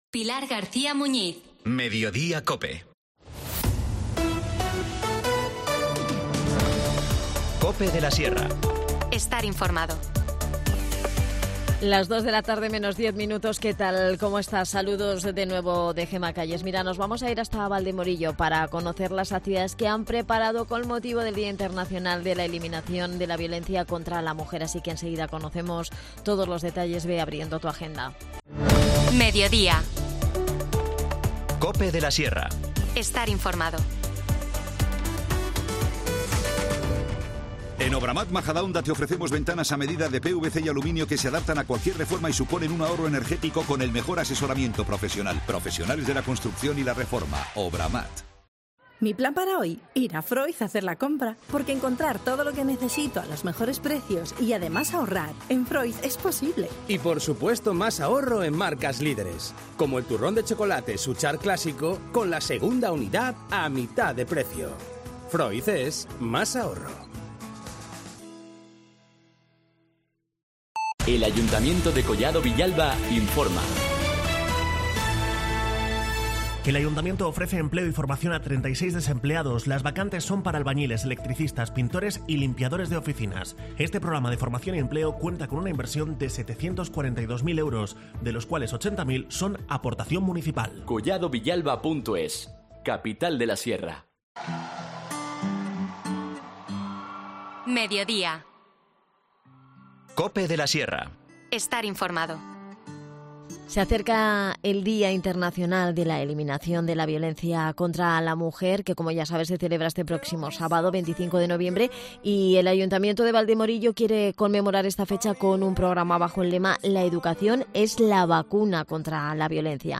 Nos detalla la programación Ana Patricia González, concejal de Servicios a la Comunidad.